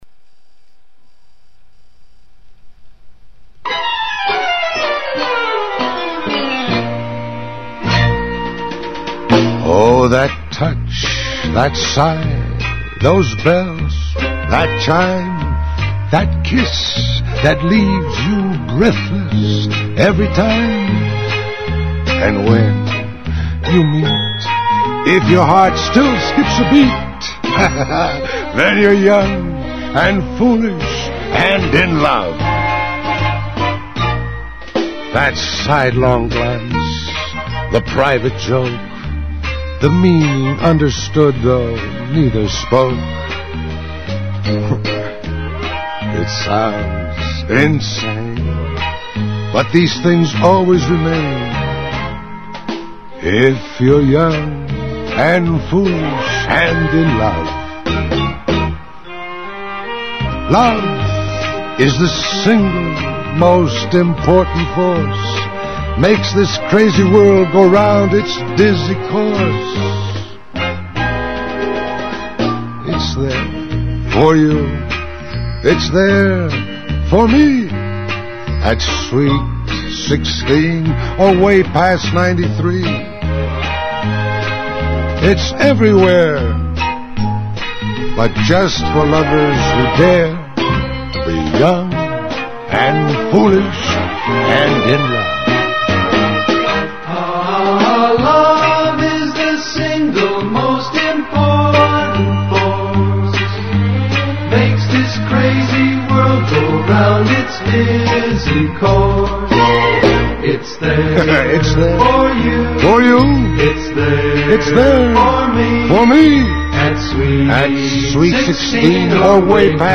Who is this from the Rex Harrison school of singing?